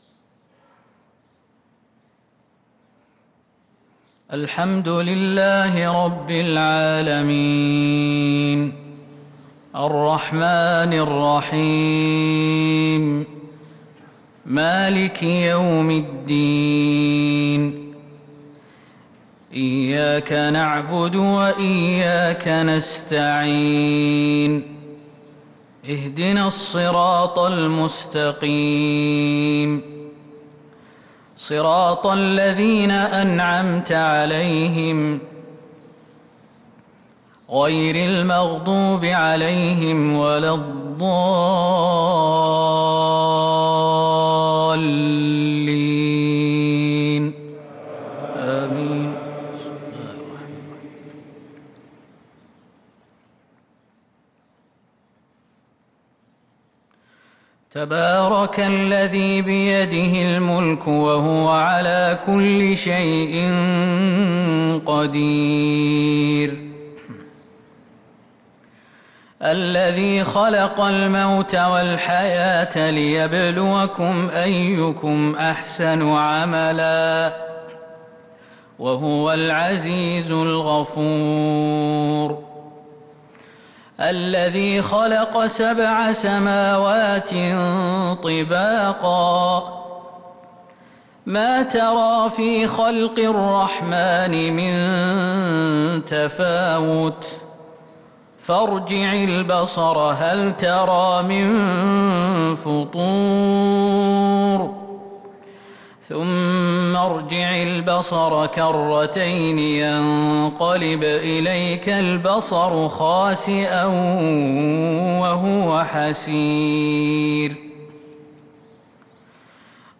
صلاة الفجر للقارئ خالد المهنا 7 رمضان 1443 هـ